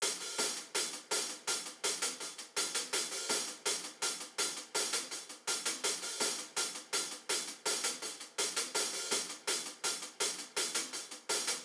Closed Hats
Have Faith Hi Hat.wav